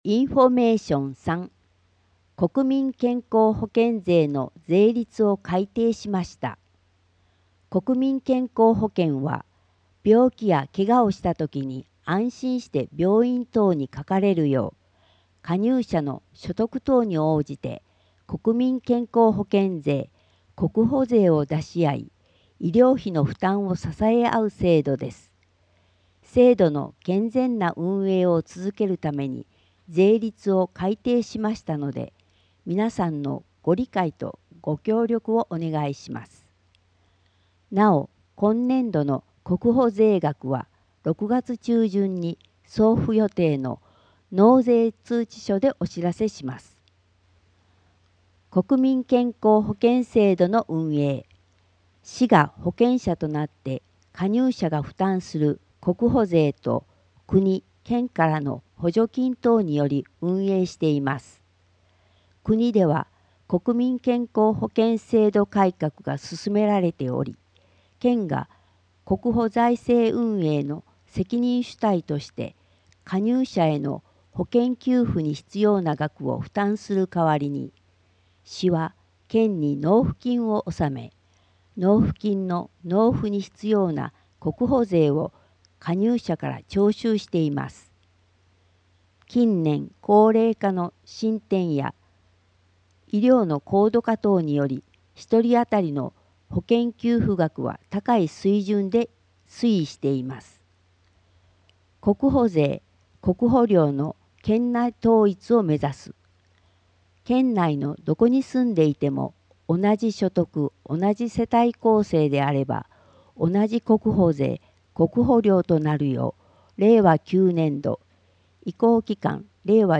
障がい者用に広報まいばらを音訳した音声データを掲載しています。音声データは音訳グループのみなさんにご協力いただき作成しています。